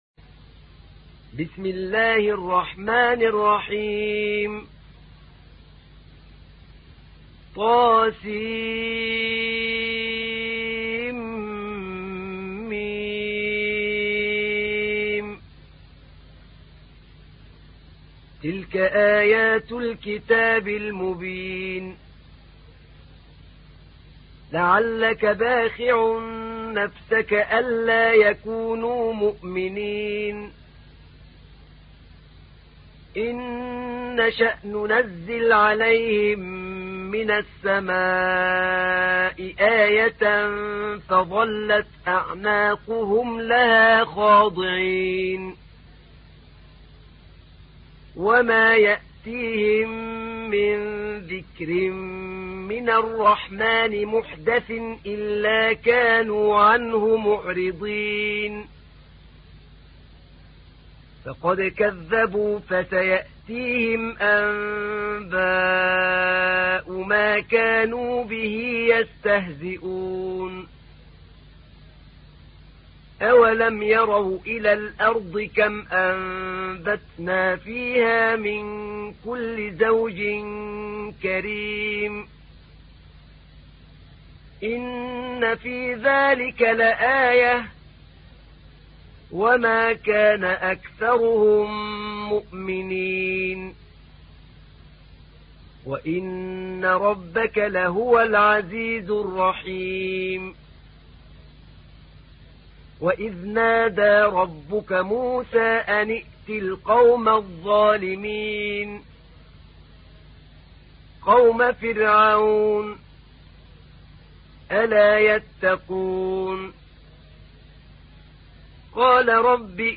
تحميل : 26. سورة الشعراء / القارئ أحمد نعينع / القرآن الكريم / موقع يا حسين